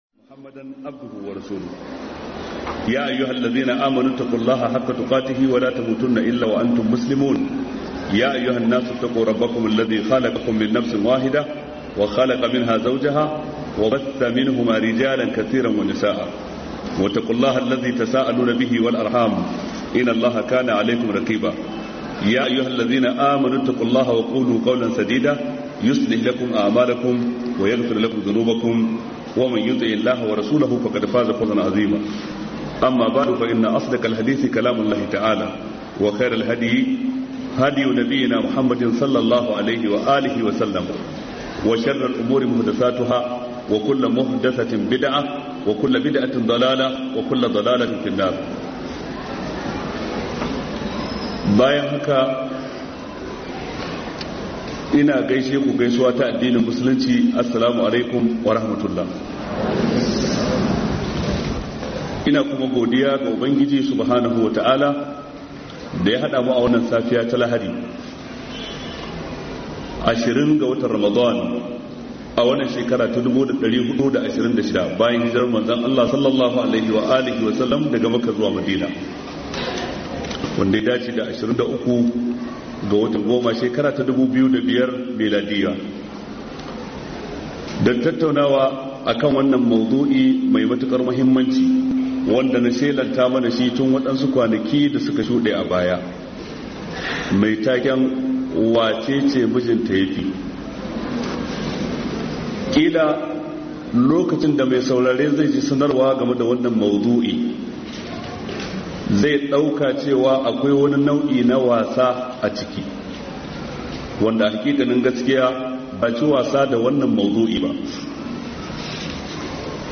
WACECE MIJINTA YAFI - MUHADARA by Sheikh Jaafar Mahmood Adam